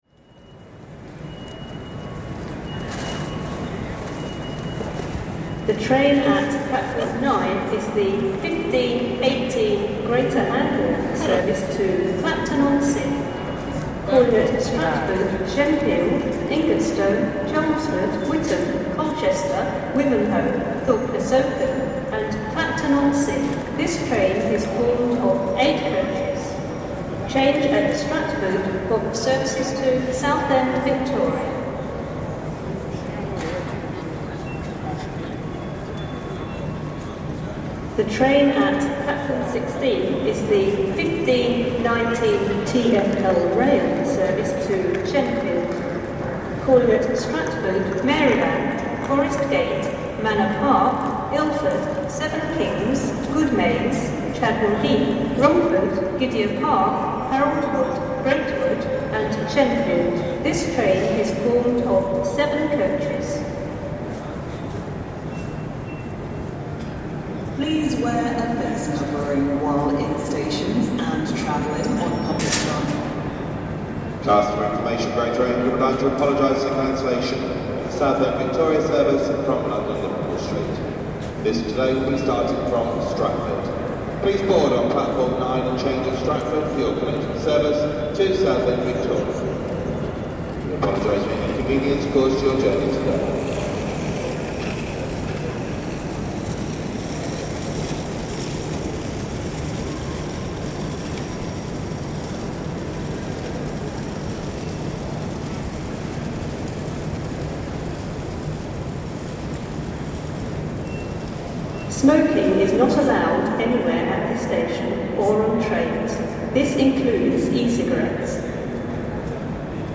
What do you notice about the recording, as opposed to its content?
An empty pandemic station